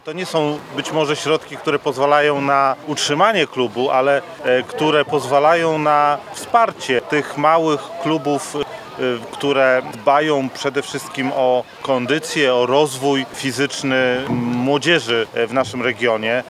Inauguracja tegorocznej edycji programu odbyła się w piątek (29.06) w Międzyszkolnym Ośrodku Sportowym z udziałem wojewody Artura Chojeckiego.